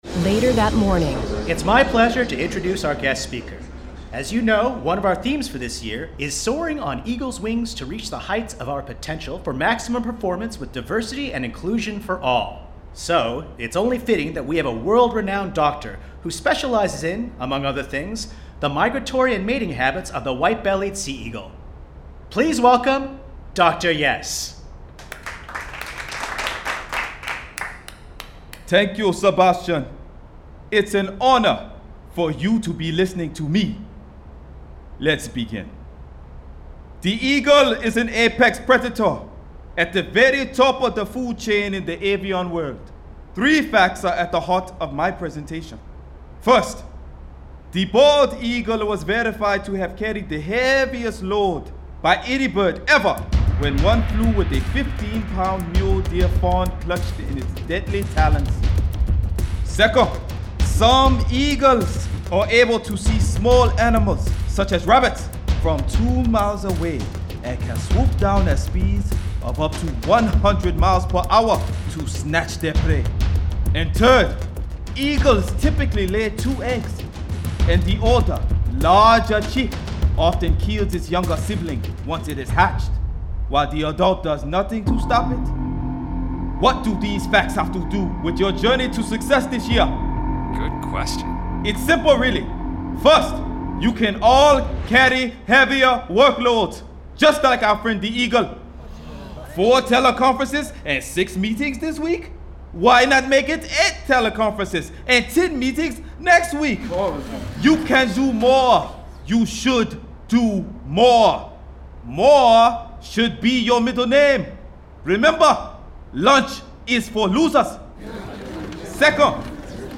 Audio Book
Listen to what happens when a strategy text book and superhero comic book collide in an entertaining and educational tour de force starring more than 20 professional voice actors, epic music and Emmy-award winning sound.